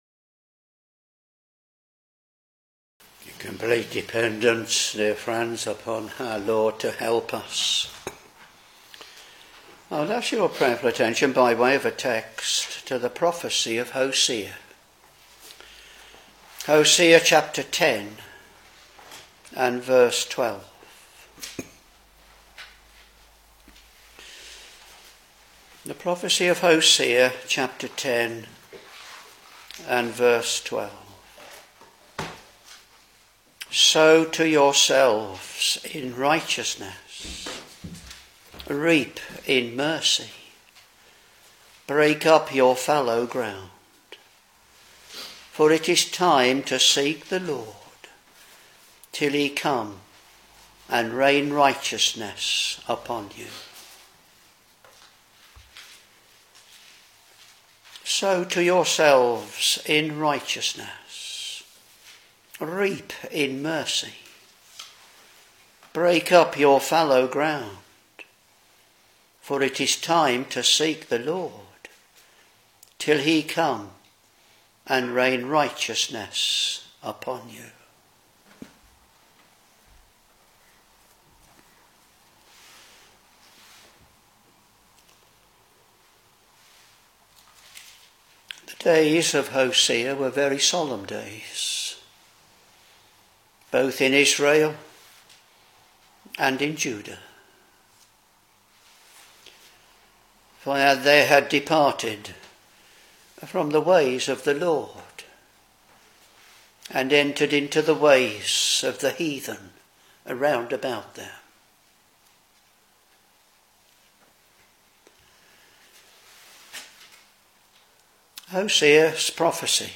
Sermons Hosea Ch.10 v.12 Sow to yourselves in righteousness, reap in mercy; break up your fallow ground: for it is time to seek the LORD, till he come and rain righteousness upon you.